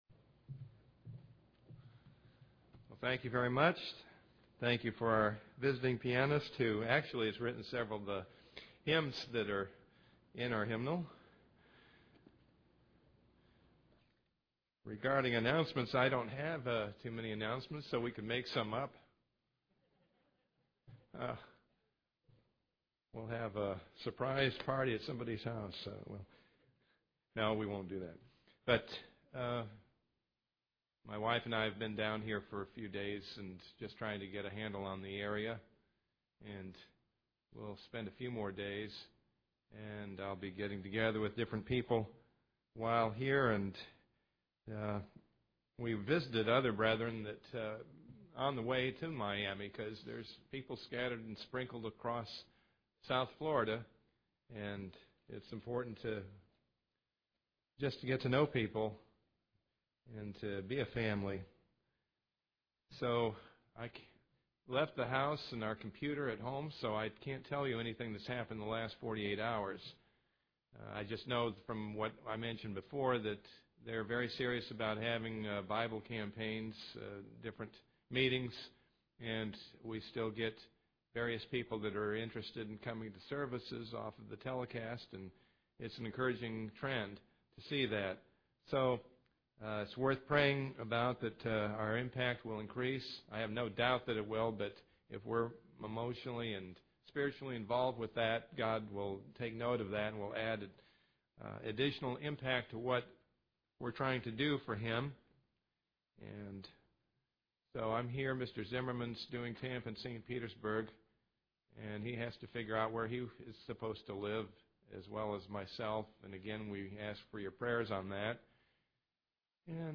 Given in Ft. Myers, FL
UCG Sermon Studying the bible?